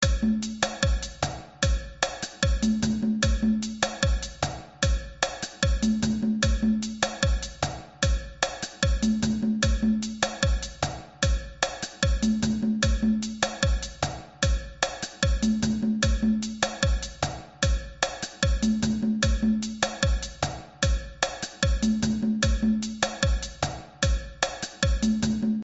Zouk audio file
Here's an audio file of Zouk groove with drums, congas, bell, bass tom...
Tom and bell are playing by one person. The rythm play by bass tom + bell is the ska rythm.